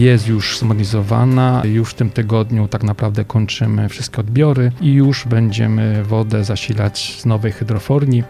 Ta hydrofornia musiała być zmodernizowana, mówi wójt Andrzej Bracha: